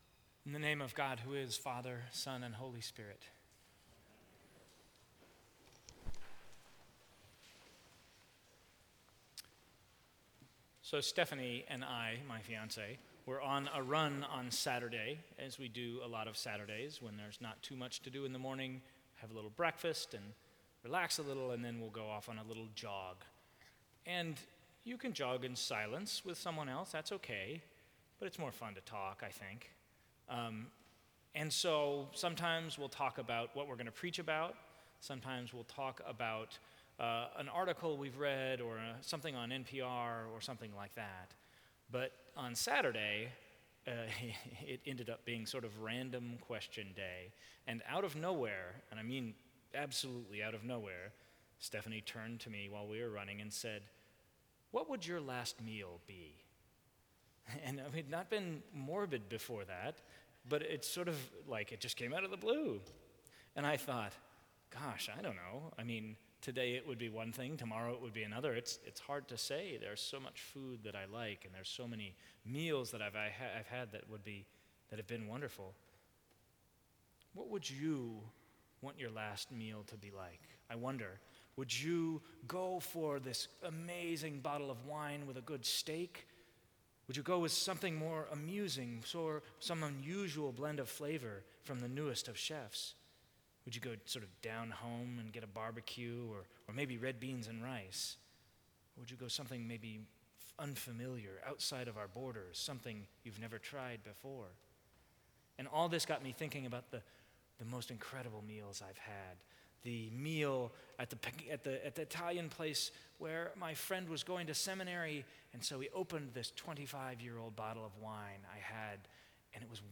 Sermons from St. Cross Episcopal Church
Sunday Sermon